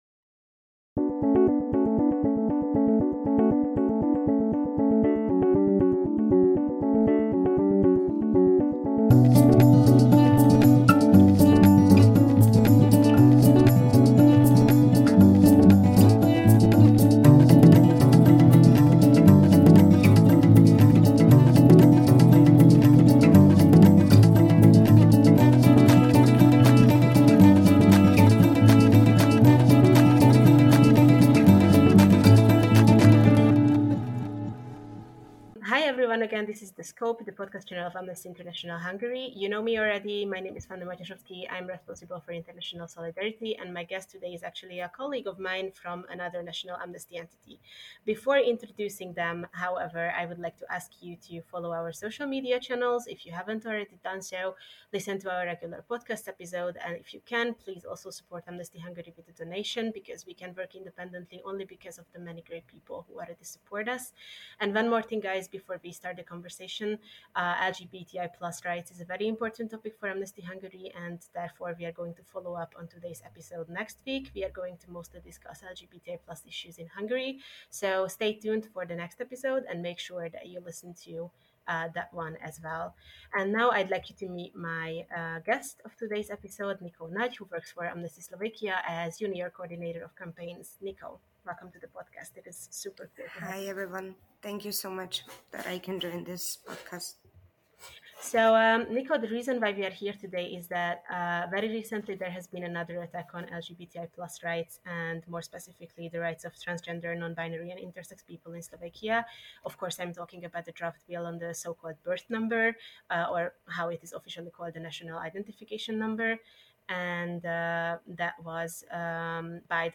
Prejavy
Ďakujeme všetkým ľuďom, ktorí si dňa 17. mája 2023 v Bratislave prišli pripomenúť Medzinárodný deň boja proti homofóbii, bifóbii a transfóbii (IDAHOBIT 2023), a to aj napriek nepriaznivému počasiu.
Na nasledujúcich odkazoch si môžete prečítať prejavy, ktoré zazneli počas zhromaždenia.